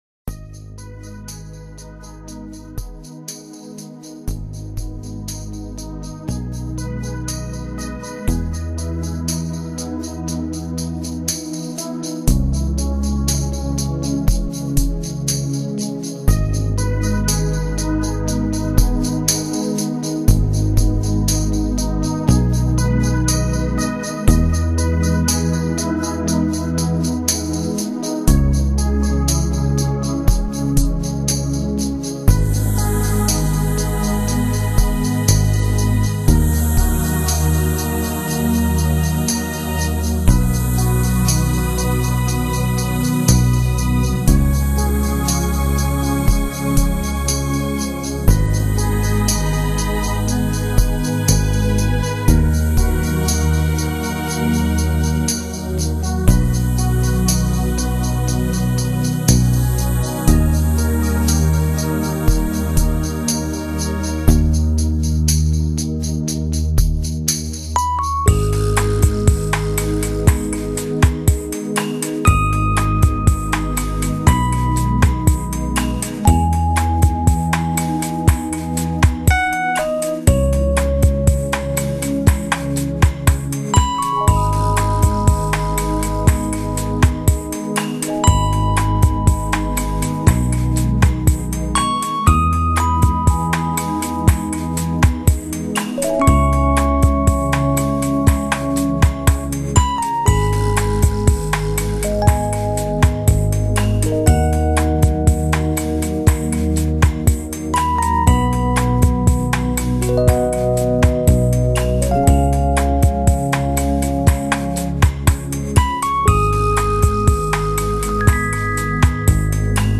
Genre: New Age, Relax, Meditative, Instrumental